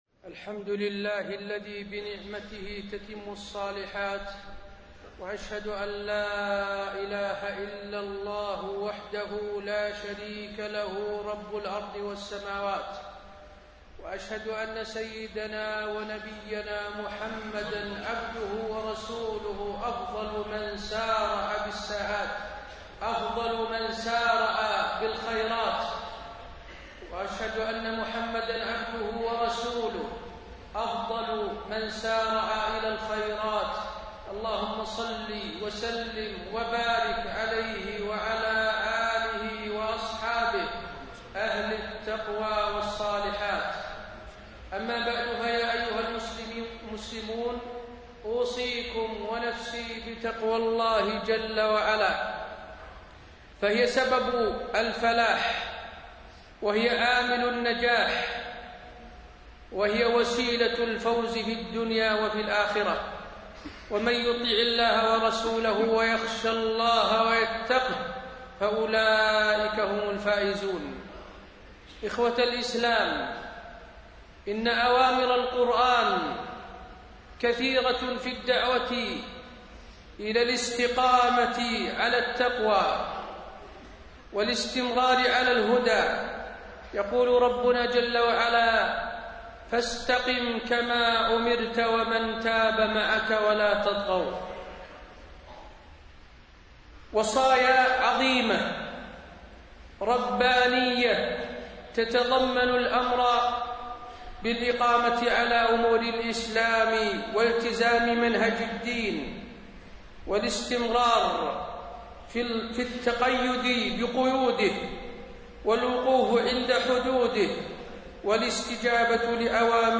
تاريخ النشر ٢ ذو القعدة ١٤٣٢ هـ المكان: المسجد النبوي الشيخ: فضيلة الشيخ د. حسين بن عبدالعزيز آل الشيخ فضيلة الشيخ د. حسين بن عبدالعزيز آل الشيخ رسالة إلى الحكام والمحكومين